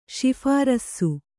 ♪ śi'hārassu